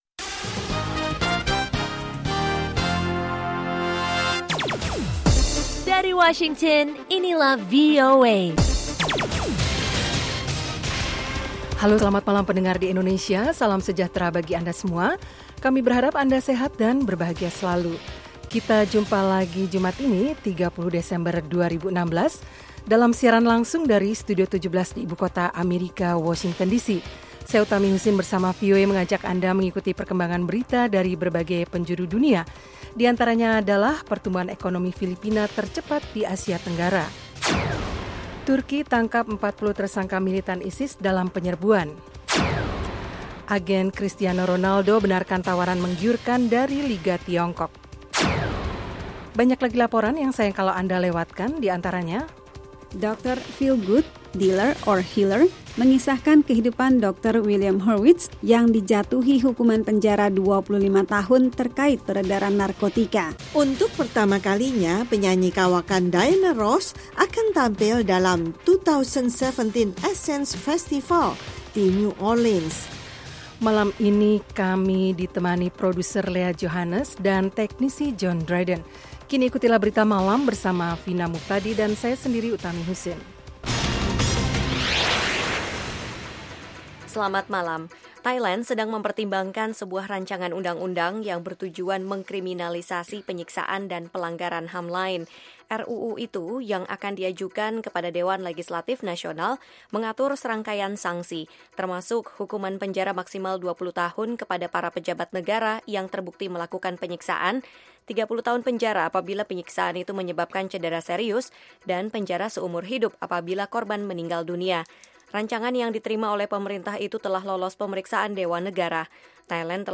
Selain padat dengan informasi, program ini sepanjang minggu menyuguhkan acara yang bernuansa interaktif dan penuh hiburan.
Kami menyajikan berbagai liputan termasuk mengenai politik, ekonomi, pendidikan, sains dan teknologi, Islam dan seputar Amerika. Ada pula acara musik lewat suguhan Top Hits, music jazz dan country.